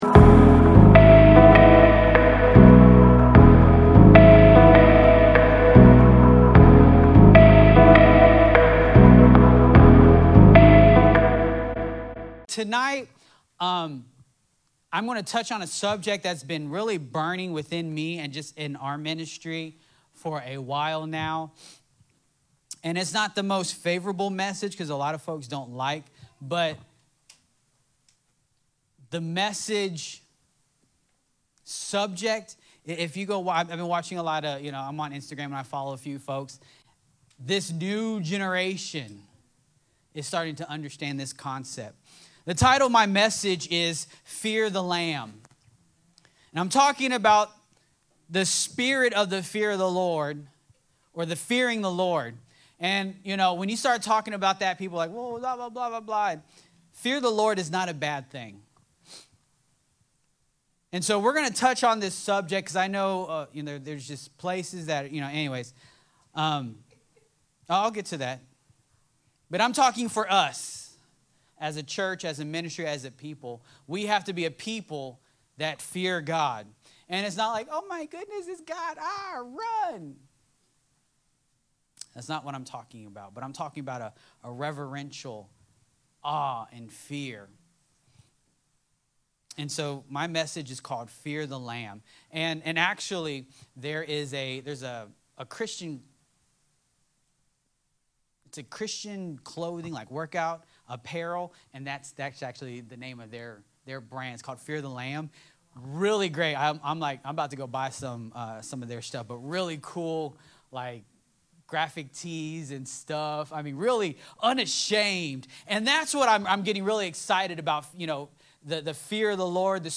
Sermons | Forerunner Church